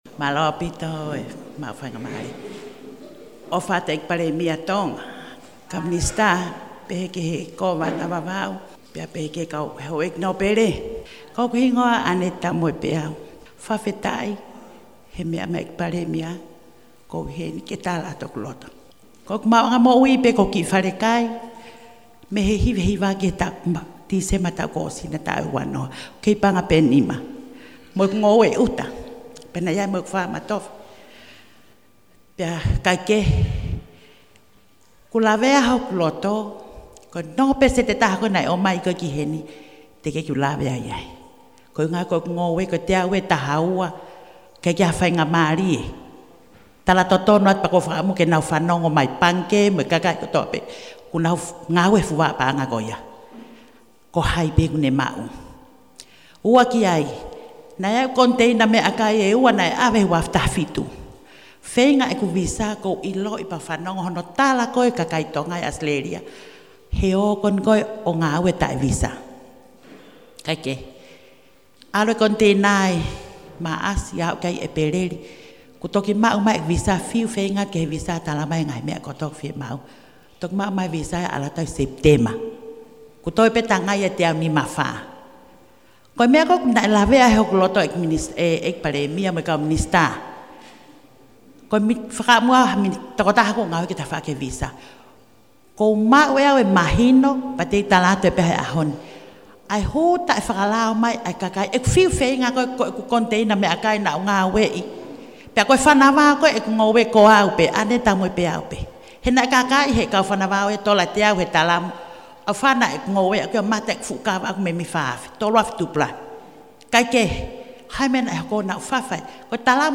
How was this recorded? An audio of the Prime Minister’s meeting in Vava’u was provided by the Prime Minister’s office and transcribed and translated into English by Kaniva News.